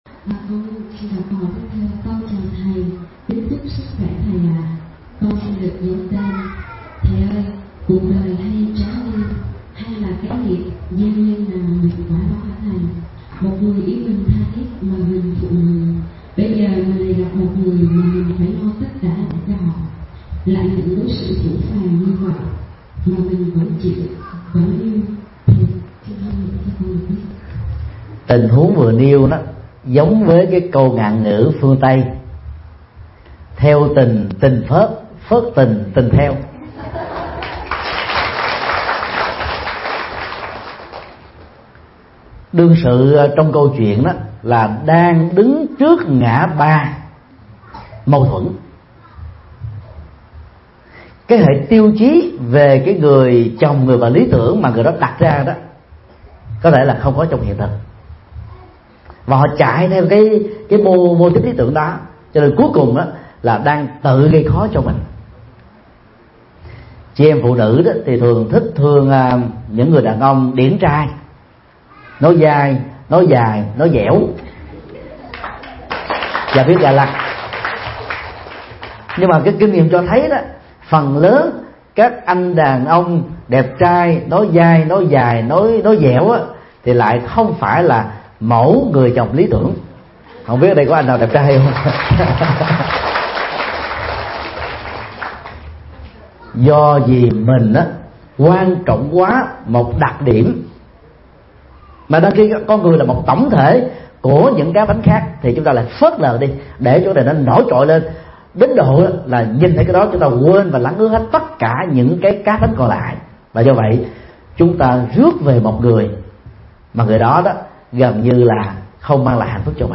Vấn đáp: Theo tình – tình phớt, phớt tình – tình theo